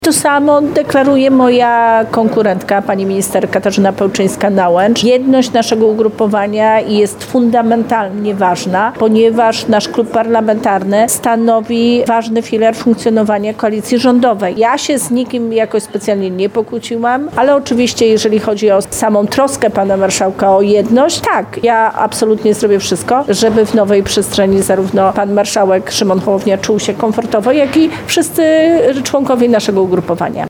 - Polska 2050 jest jedna i o jej jedność zawsze będę walczyć - mówiła dziś (28.01) w Lublinie minister klimatu i środowiska, Paulina Henning-Kloska.